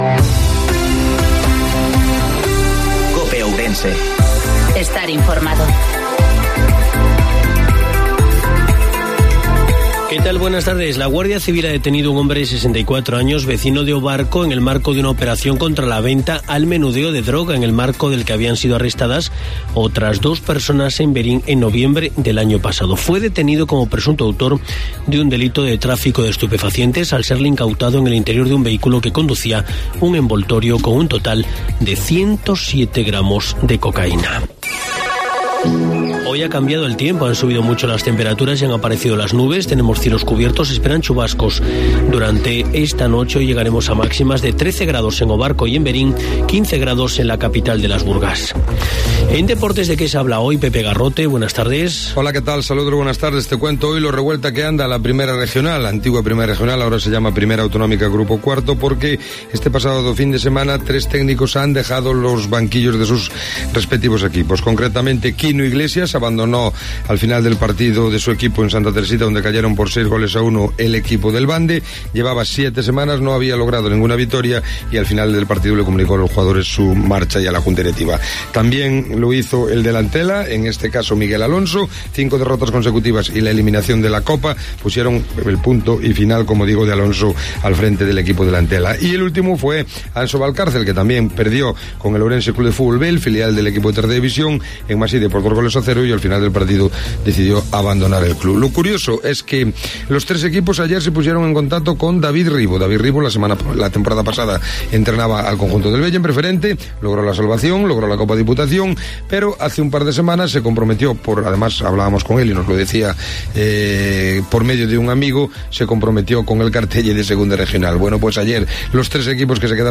INFORMATIVO MEDIODIA COPE OURENSE